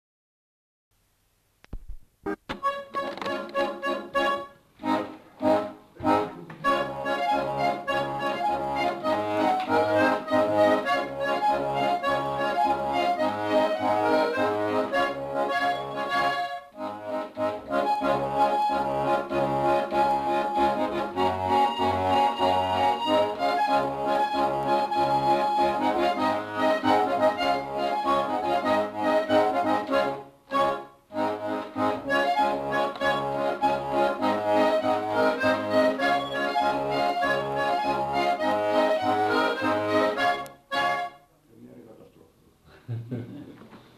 Chants et musiques à danser de Bigorre interprétés à l'accordéon diatonique
enquêtes sonores